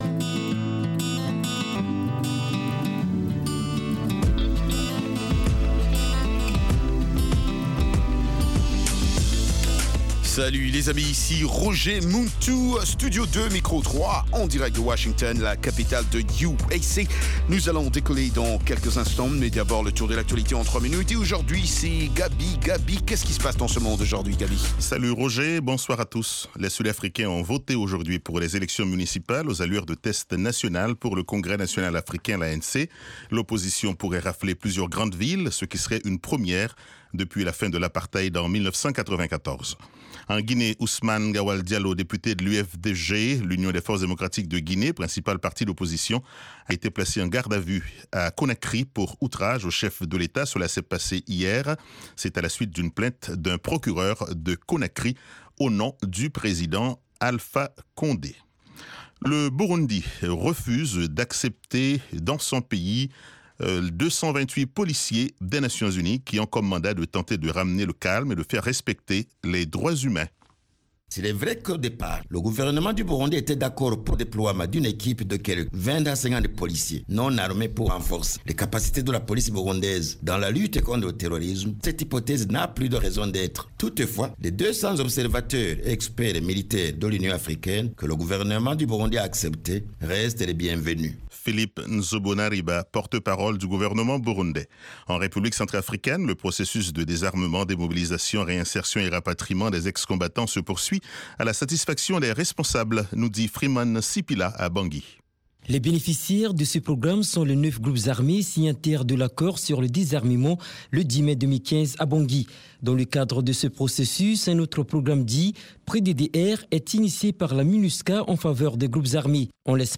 RM Show -Musique internationale & comedie